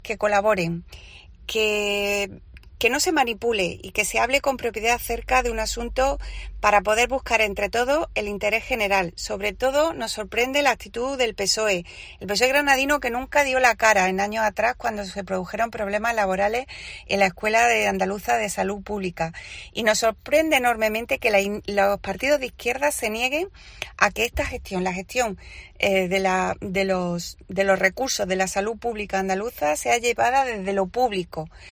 Rosa Fuentes, parlamentaria del PP